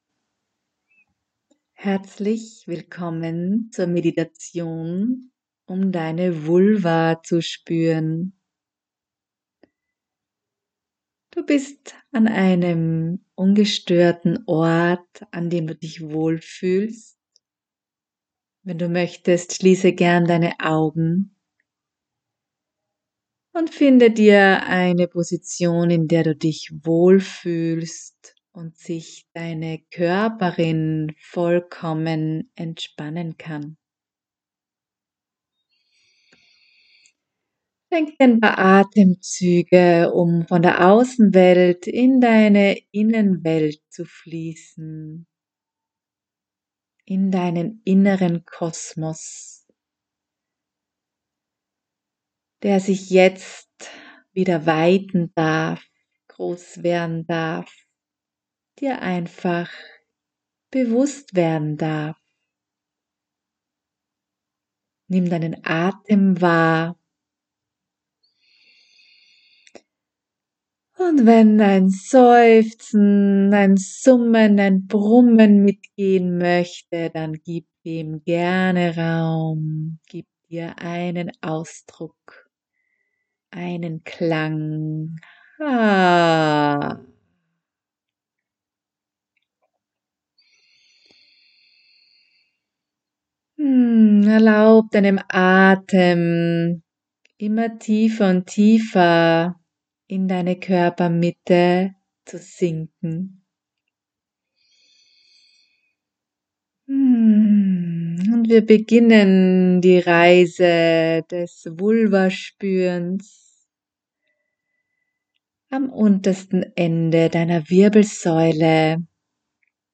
Audio-Meditationen aus dem Buch
Meditation-Die-Vulva-spueren.mp3